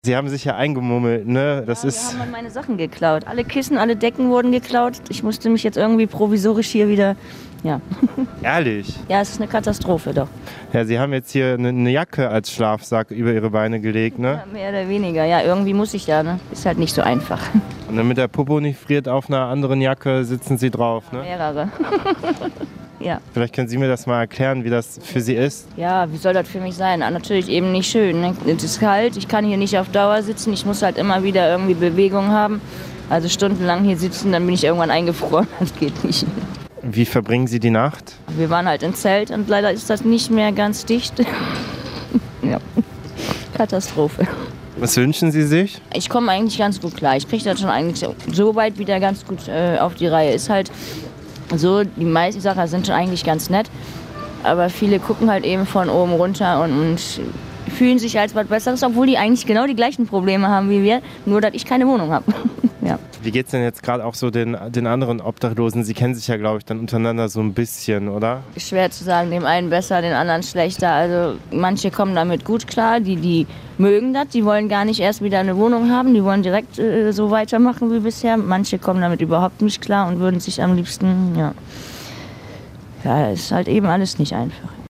Wir haben mit einer Obdachlosen gesprochen und sie gefragt, wie sie mit der Kälte zurecht kommt.
_-_obdachlose_interview_1.mp3